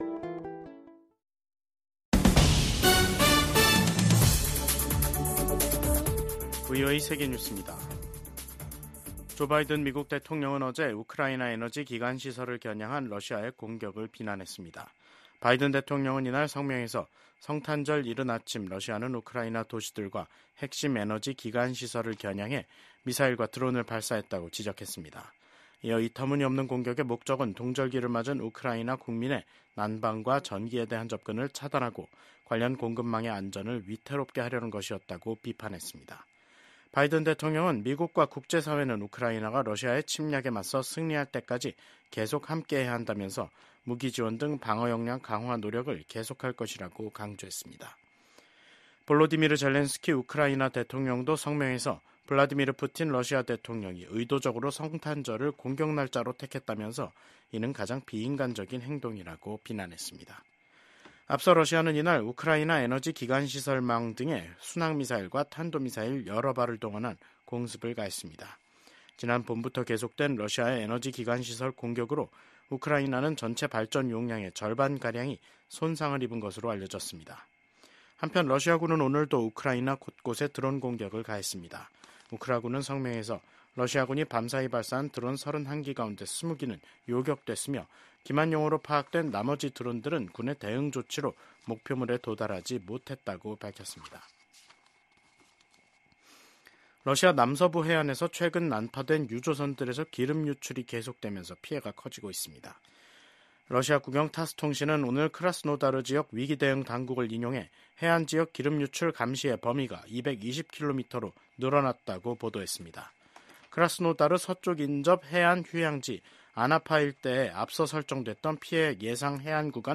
VOA 한국어 간판 뉴스 프로그램 '뉴스 투데이', 2024년 12월 26일 3부 방송입니다. 비상계엄 사태와 대통령 직무정지로 한국 정정 불안이 지속되는 가운데 한국과 중국 두 나라는 외교장관 간 전화통화를 하는 등 소통을 재개했습니다. 미국 의회 중국위원회가 지난 1년 간의 조사 및 활동을 기술한 연례 보고서를 통해 중국 내 탈북민 문제와 관련된 심각한 인권 침해에 우려를 표명했습니다.